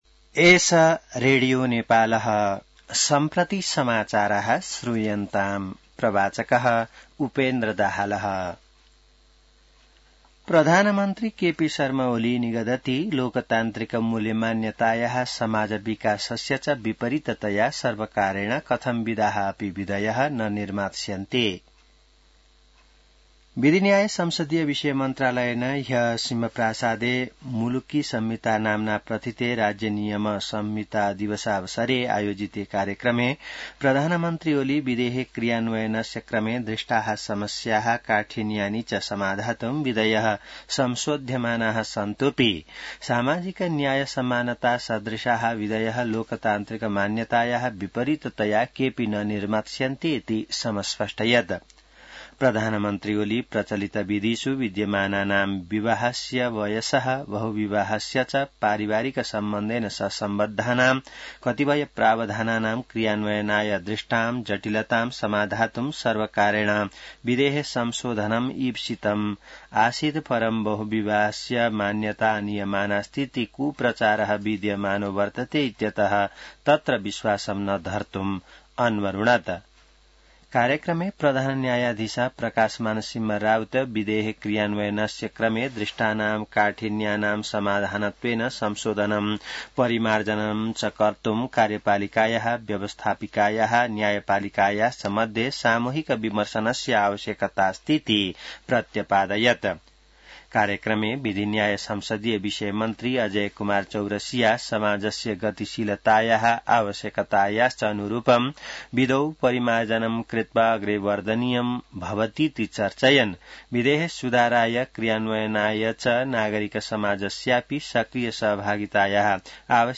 संस्कृत समाचार : २ भदौ , २०८२